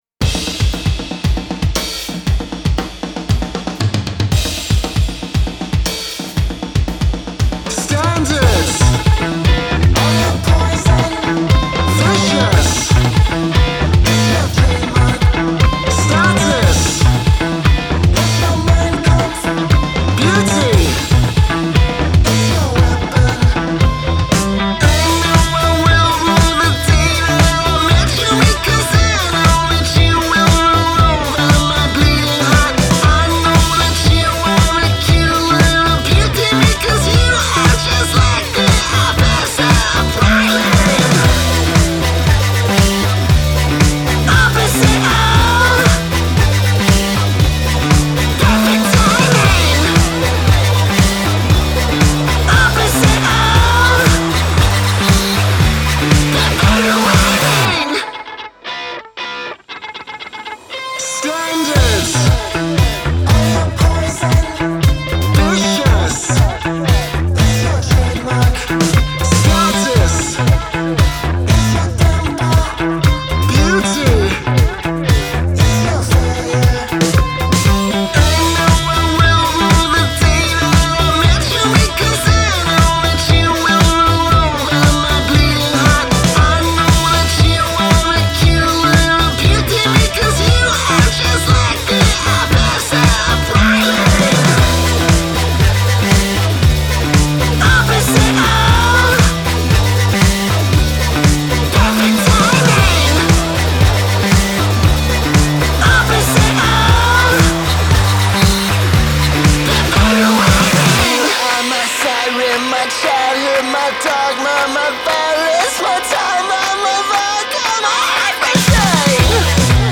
Genre: Indie, Dance Punk